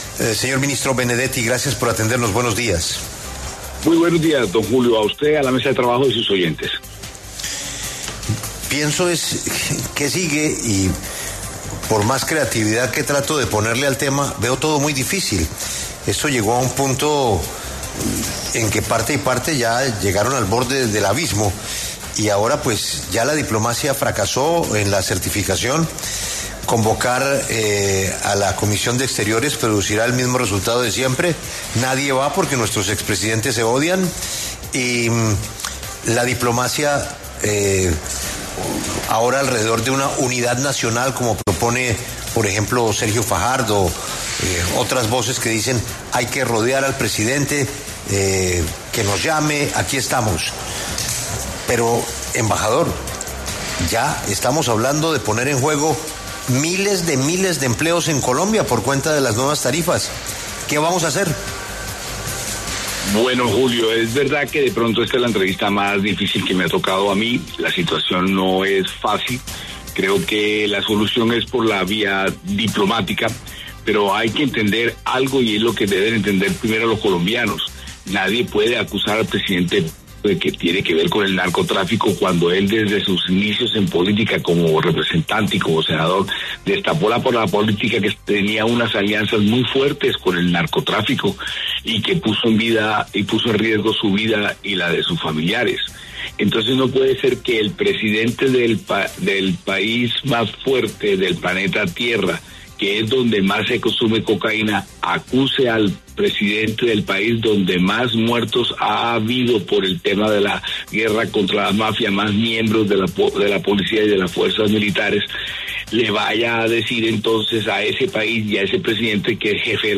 El ministro del Interior, Armando Benedetti, conversó con La W sobre la tensión que se desató entre los Gobiernos de Colombia y EE.UU. por la lucha contra las drogas.